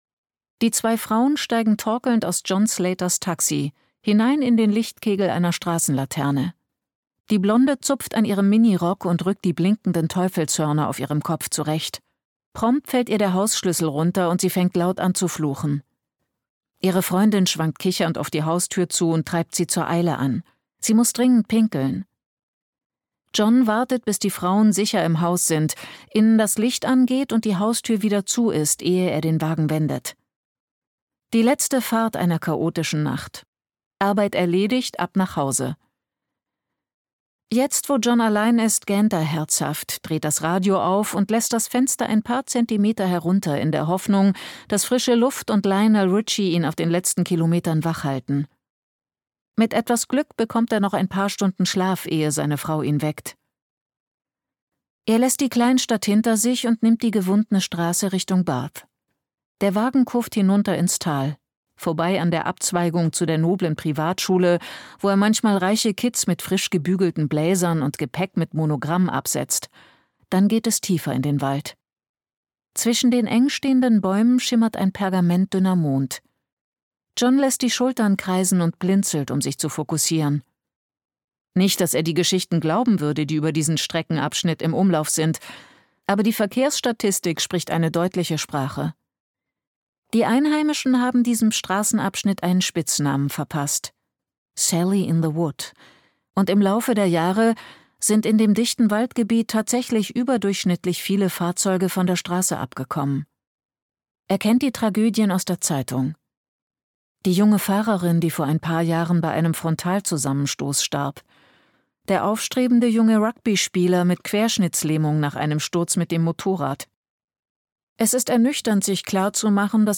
Das Internat - Hannah Richell | argon hörbuch
Gekürzt Autorisierte, d.h. von Autor:innen und / oder Verlagen freigegebene, bearbeitete Fassung.